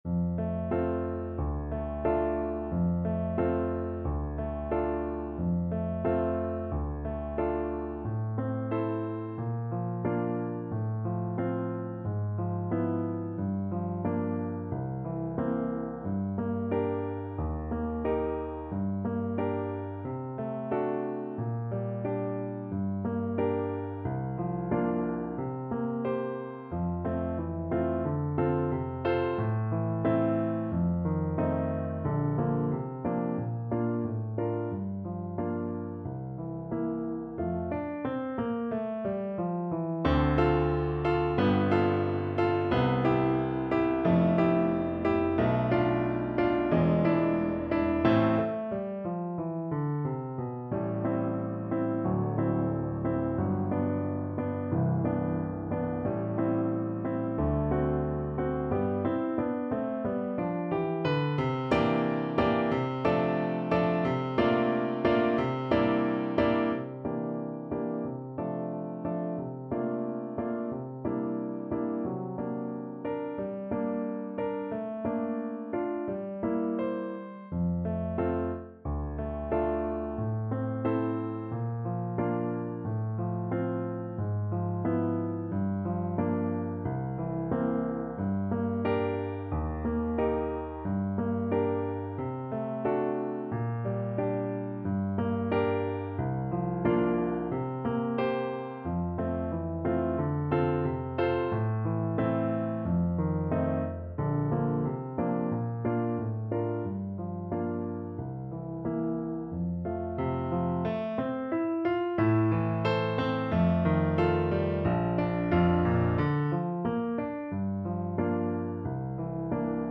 2/4 (View more 2/4 Music)
~ = 100 Allegretto con moto =90
Classical (View more Classical French Horn Music)